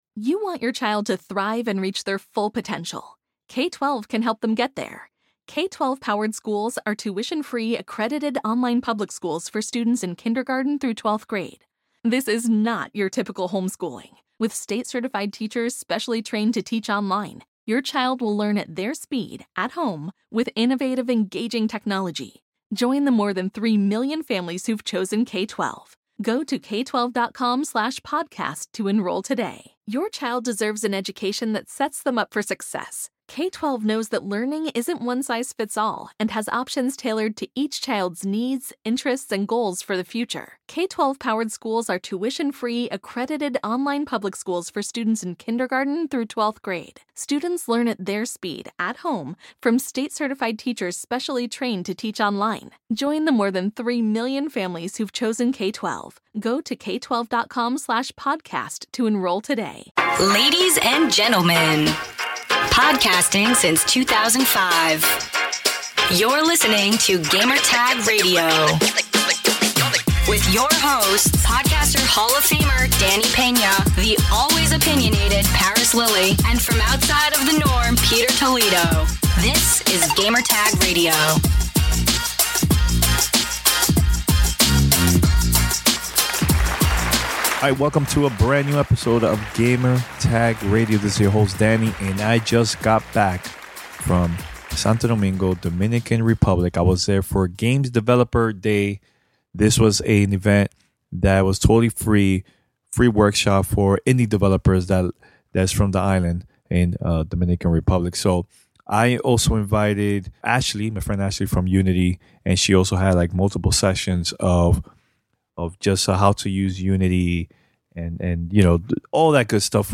Live coverage from the free workshop, 'Game Developer Day 2019' that took place at Intec in Santo Domingo, Dominican Republic.